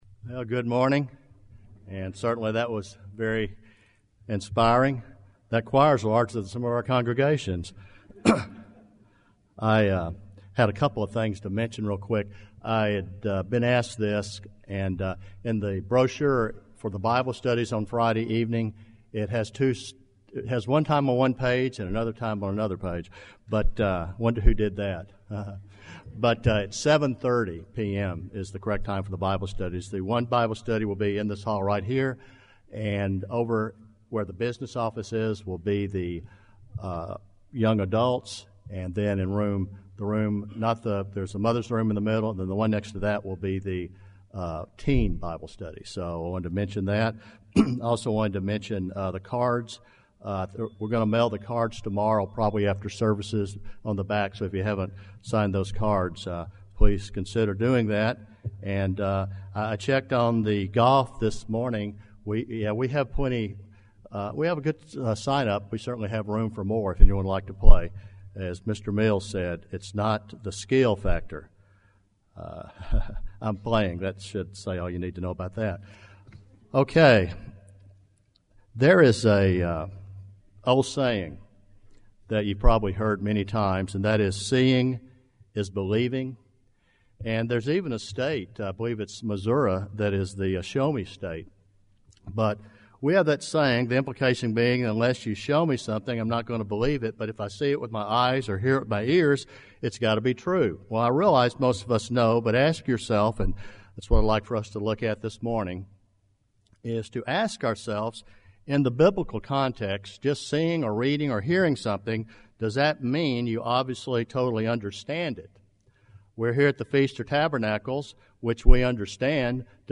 This sermon was given at the Jekyll Island, Georgia 2016 Feast site.